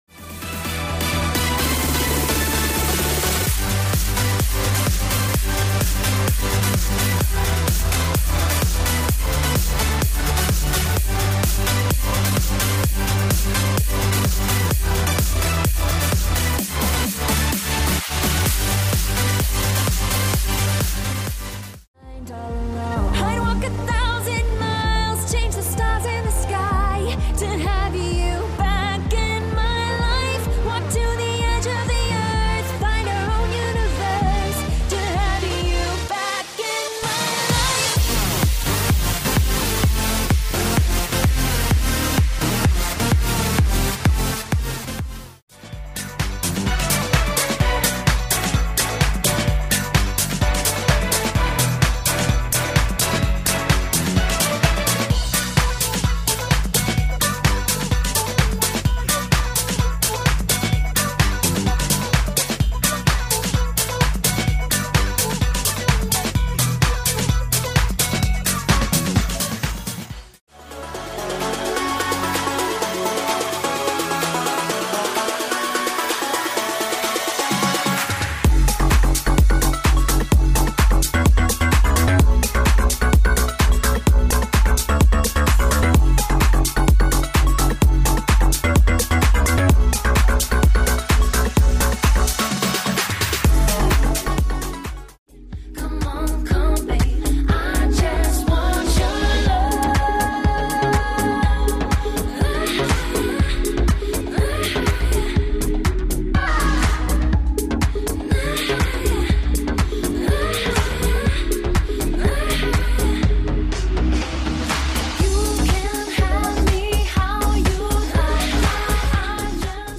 styl - house/clubbing/dance